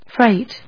/frάɪt(米国英語)/